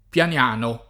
[ p L an L# no ]